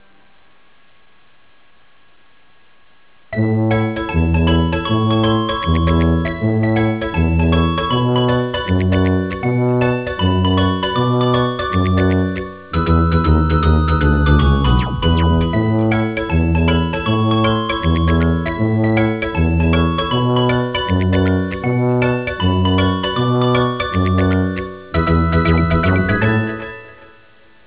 -This one's WAY cute!!